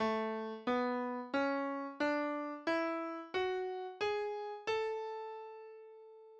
a_major_nature_midi.mp3